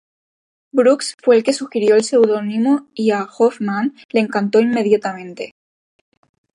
Pronúnciase como (IPA)
/immeˌdjataˈmente/